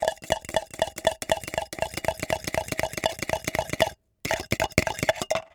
Cooking Stirring Sound
household